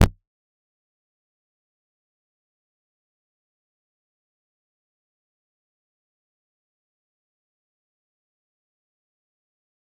G_Kalimba-E0-f.wav